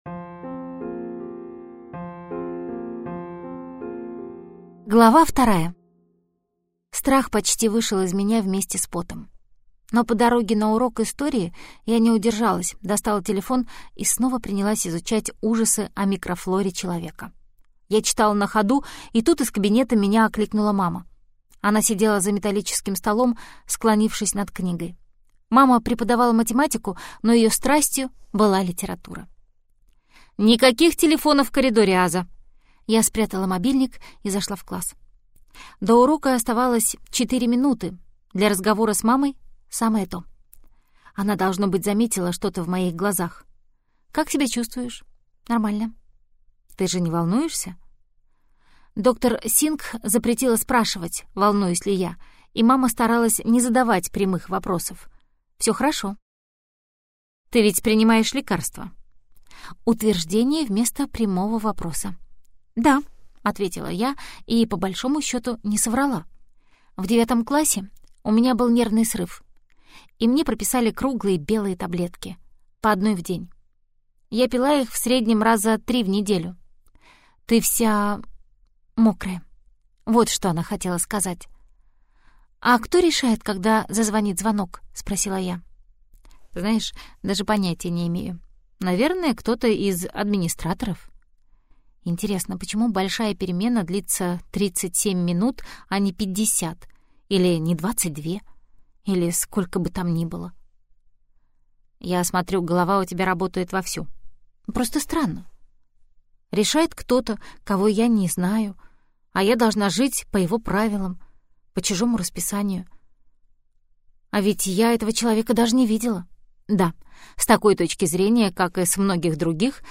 Аудиокнига Черепахи – и нет им конца - купить, скачать и слушать онлайн | КнигоПоиск